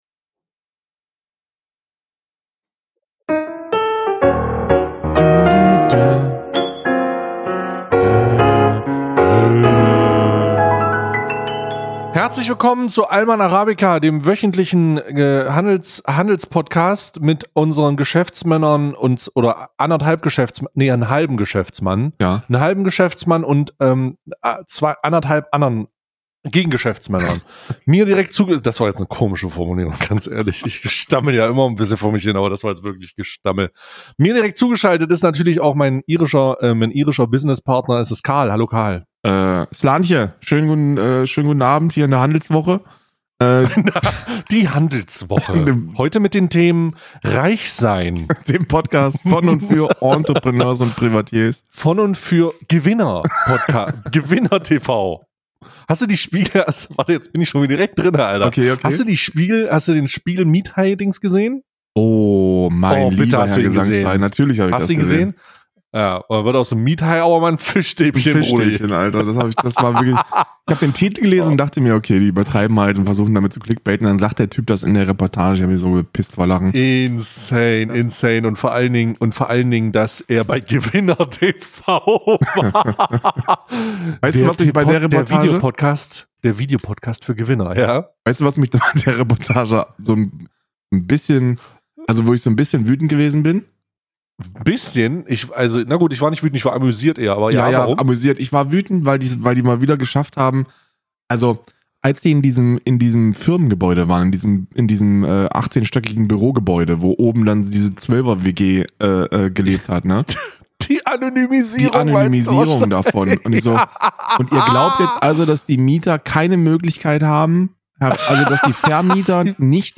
zwei hochgewichtige Urdeutsche mit ausgeprägter suburbaner Sprachkultur pressen erfrischend polarisierende Meinungen satirisch in perfekt maßgeschneiderte Siebträger